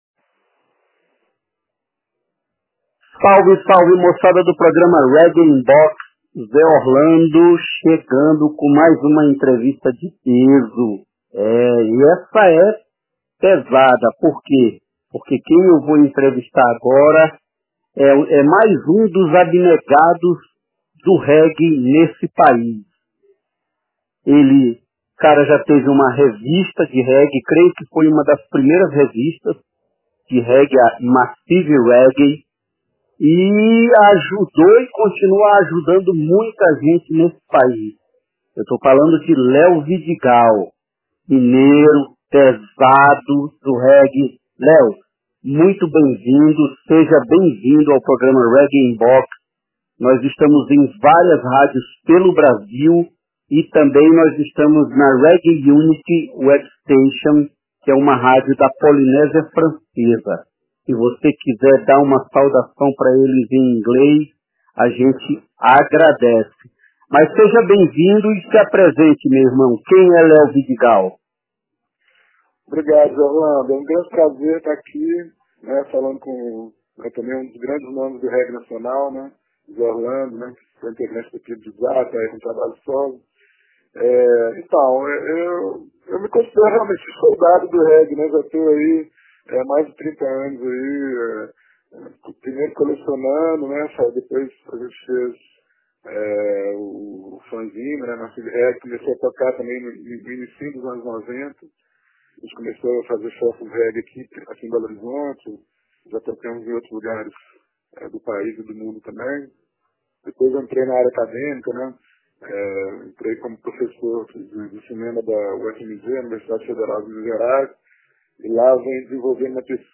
RIB Entrevista